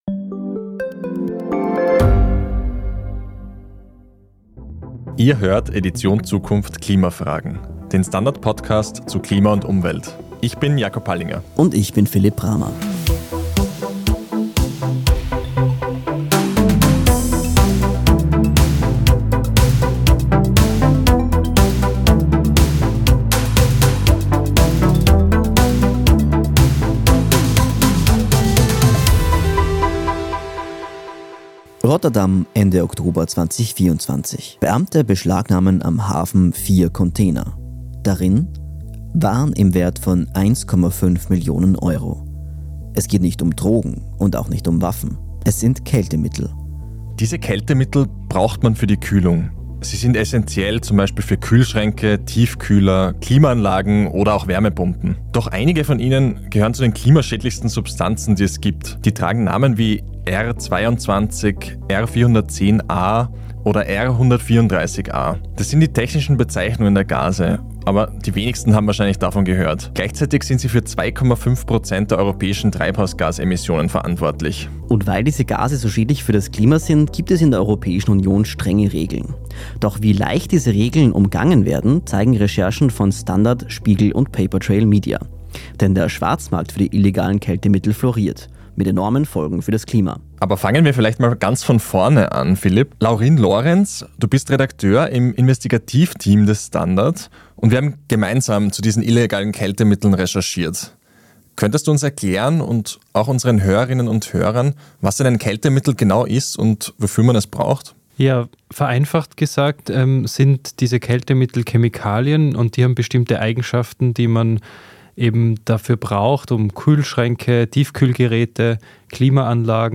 Edition Zukunft ist der STANDARD-Podcast über das Leben und die Welt von morgen. Die Redaktion spricht mit Experten über Entwicklungen, die unseren Alltag verändern - von künstlicher Intelligenz und Robotern bis hin zu Migration und Klimawandel.